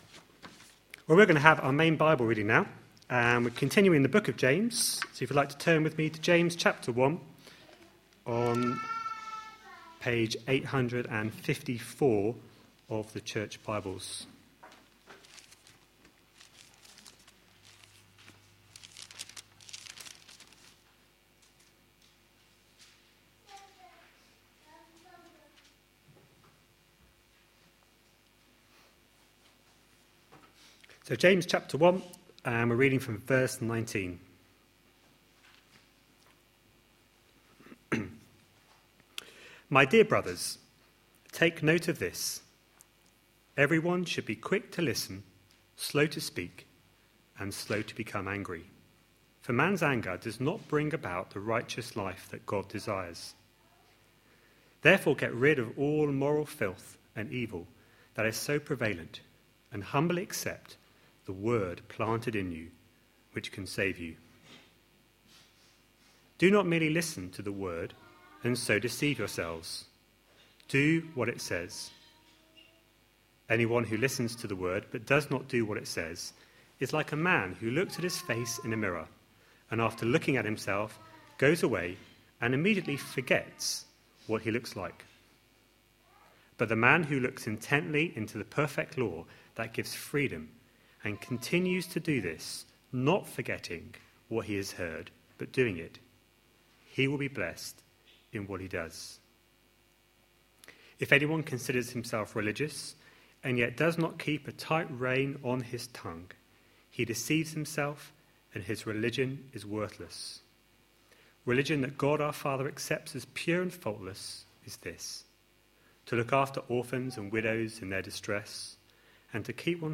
A sermon preached on 10th May, 2015, as part of our James series.